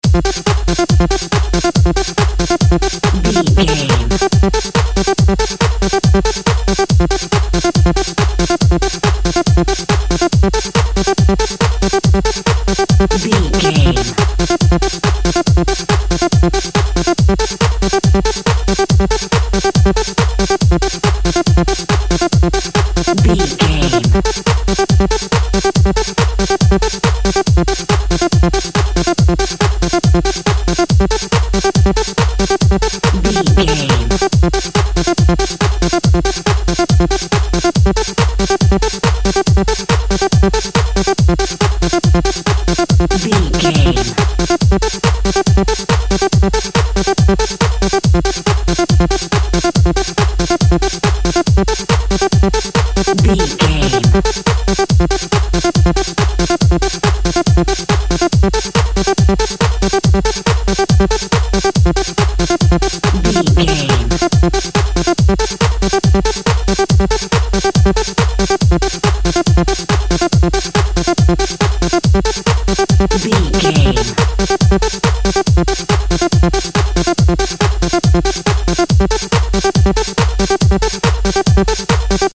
Aeolian/Minor
driving
energetic
futuristic
hypnotic
industrial
drums
synthesiser
synth lead
synth bass